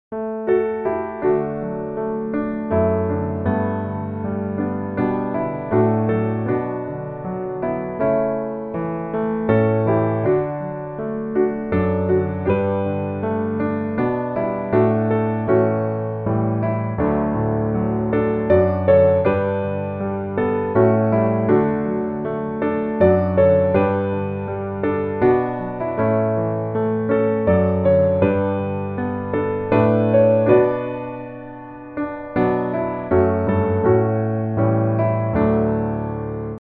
D Majeur